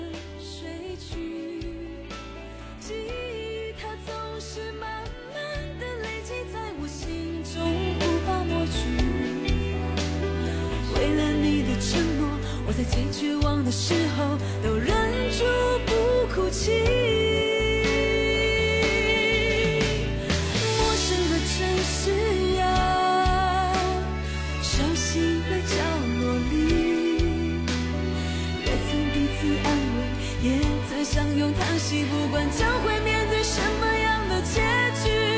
Monitor Speaker sound after issue happened (212.65 KB, audio/mpeg)
Use aplay software to play music on 2 monitors' Speaker at the same time, about 5 minute, the sound of two monitors' Speaker are abnormal, no music, but  noise;
The sound of two monitors Speaker is noise.